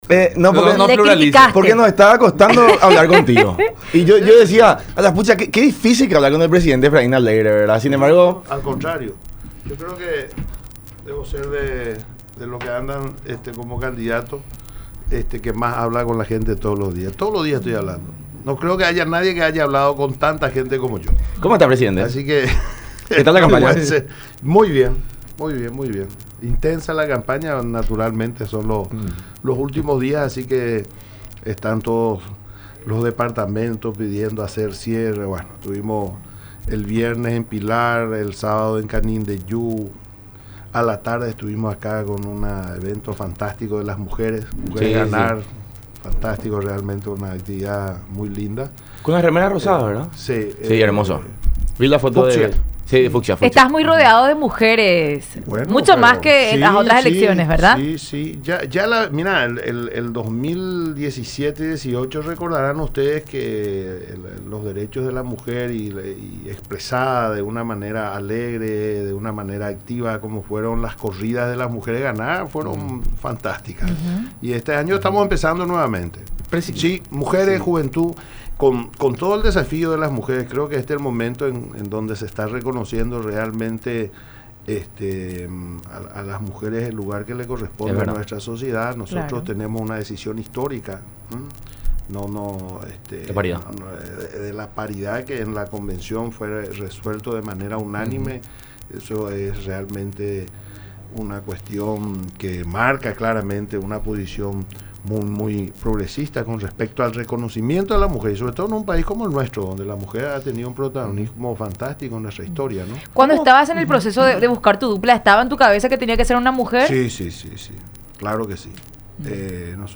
Seamos serios”, manifestó Alegre en su visita a los estudios de Unión TV y radio La Unión durante el programa La Unión Hace